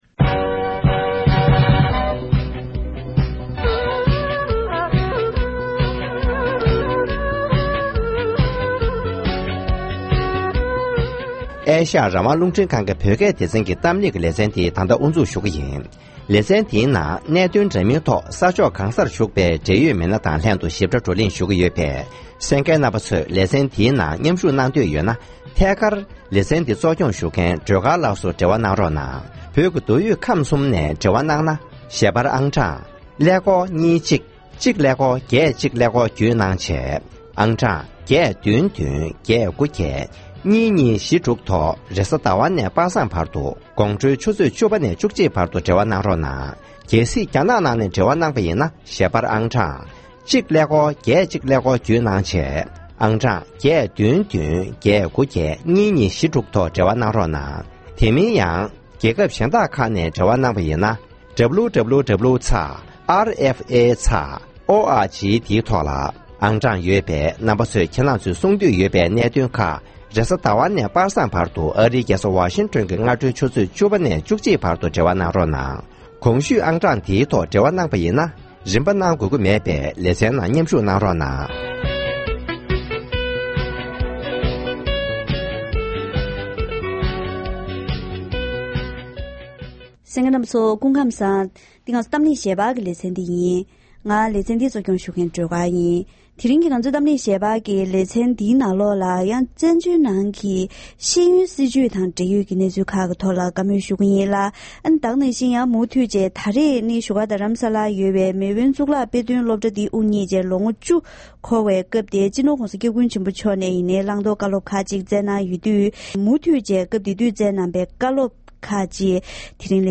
༄༅། །ཐེངས་འདིའི་གཏམ་གླེང་ཞལ་པར་ལེ་ཚན་ནང་བཙན་བྱོལ་བོད་པའི་སློབ་གྲྭ་ཁག་ཅིག་ནང་བོད་མིའི་སྒྲིག་འཛུགས་ཀྱི་ཤེས་རིག་ལྷན་ཁང་ནས་ཤེས་ཡོན་སྲིད་ཇུས་ལག་བསྟར་གནང་སྟེ་ལོ་ངོ་བཅུ་འགྲོ་ཡི་ཡོད་པས། ཤེས་ཡོན་སྲིད་ཇུས་ཁྲོད་ཀྱི་ཤུགས་རྐྱེན་དང་འགྲུབ་འབྲས་སྐོར་དང་། དེ་བཞིན་སྤྱི་ནོར་༸གོང་ས་༸སྐྱབས་མགོན་ཆེན་པོ་མཆོག་ནས་ཤེས་ཡོན་སློབ་གསོའི་སྐོར་ལ་གནང་བའི་བཀའ་སློབ་སོགས་པ་ངོ་སྤྲོད་ཞུས་པ་ཞིག་གསན་རོགས་གནང་།